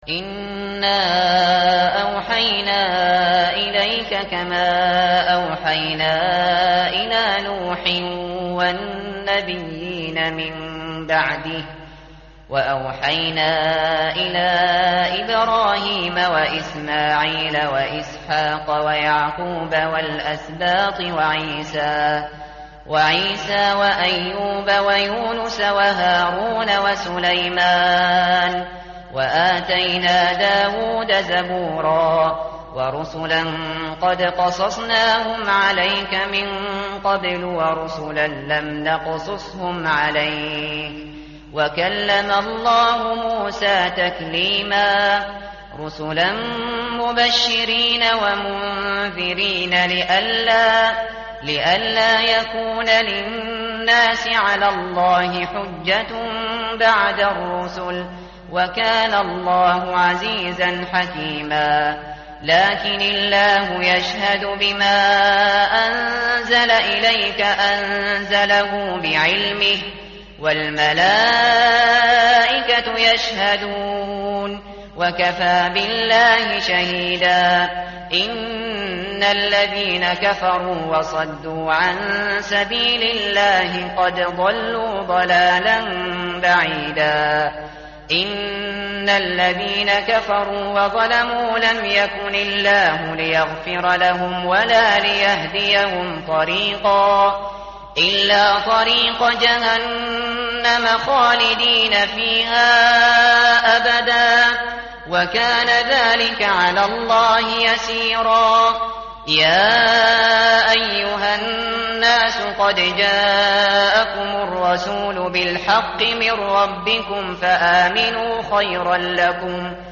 متن قرآن همراه باتلاوت قرآن و ترجمه
tartil_shateri_page_104.mp3